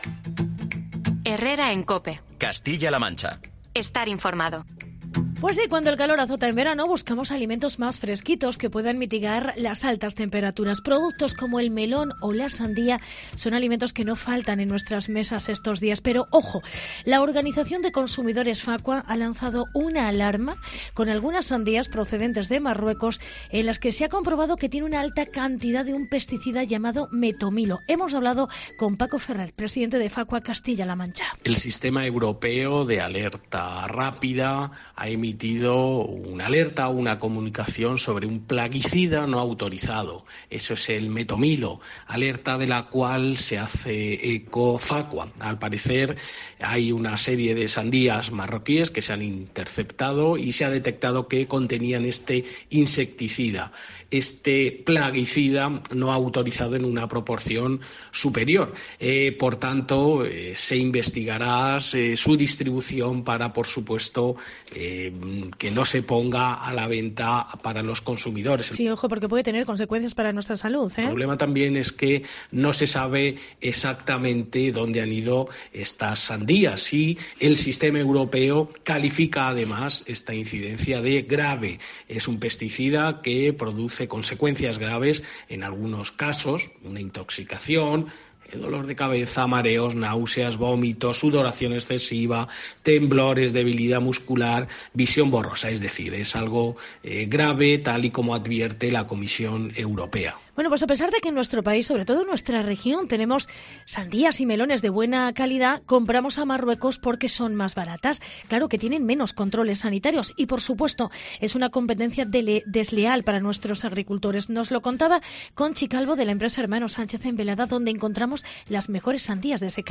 Hablamos con una de las mayores producciones de sandía en Toledo sobre el desabastecimiento y la competencia de Marruecos